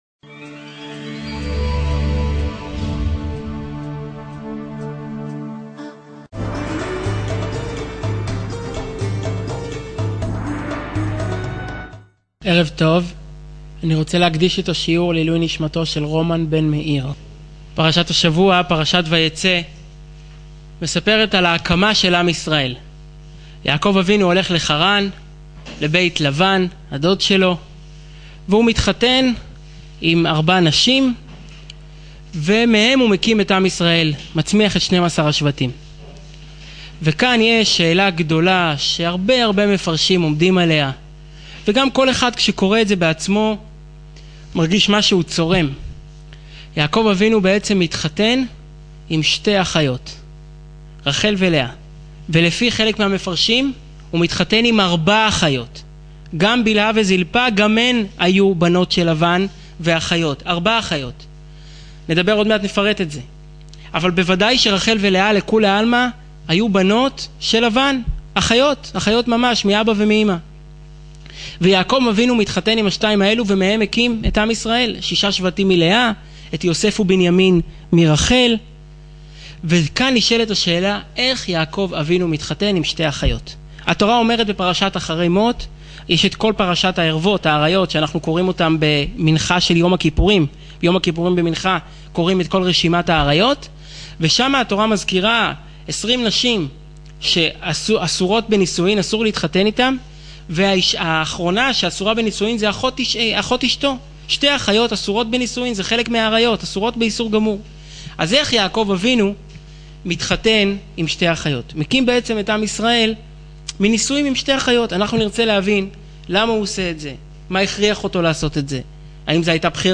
● שיעור וידאו